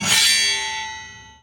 gearupsword.wav